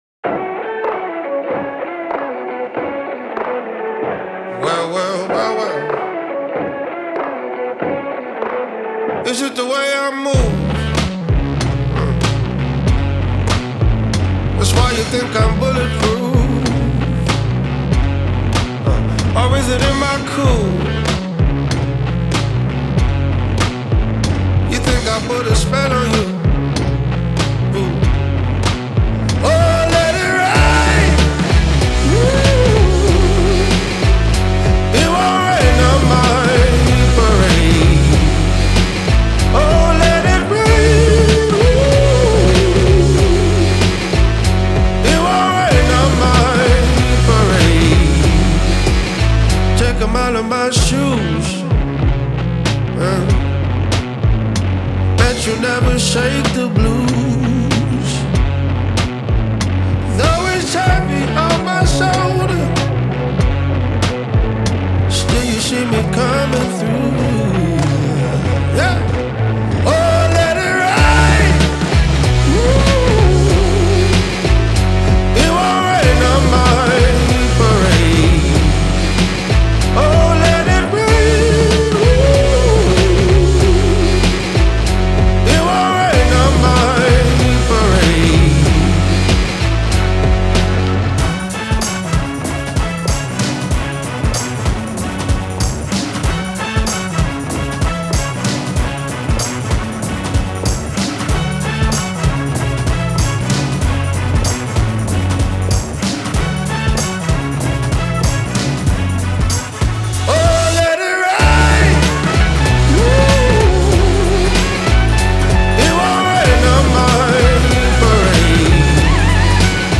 это мощная и эмоциональная песня